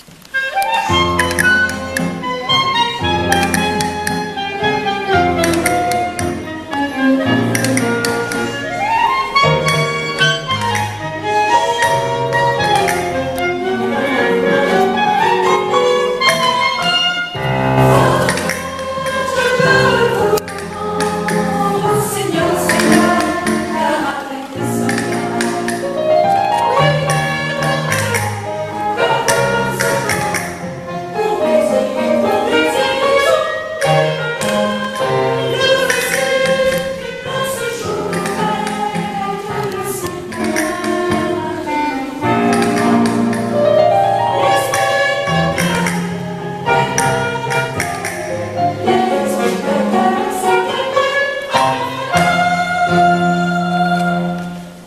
Un spectacle sur le thème de l'opéra créé et proposé
par les élèves des écoles
Gymnase de Chaponost
Le chœur est composé des classes de :
piano, vibraphone
Clarinette
Alto
Guitare
Percussions